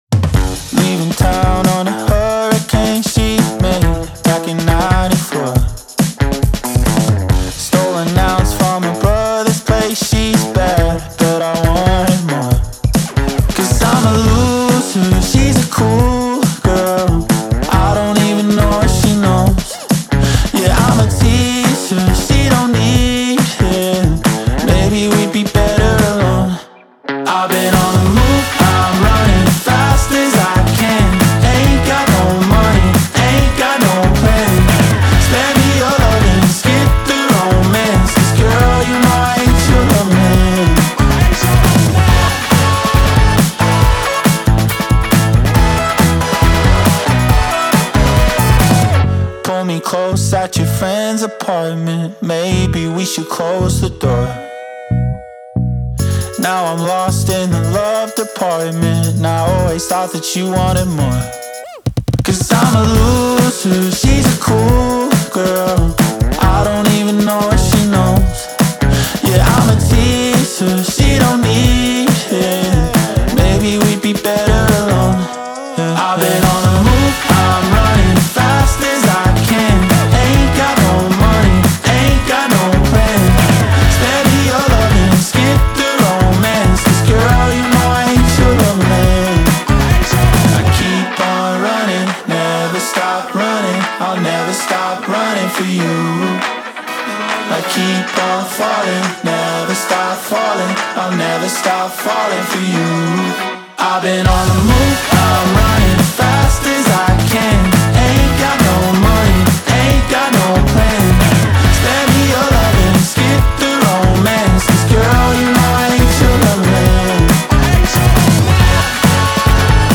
BPM138-138
Audio QualityPerfect (High Quality)
Indie song for StepMania, ITGmania, Project Outfox
Full Length Song (not arcade length cut)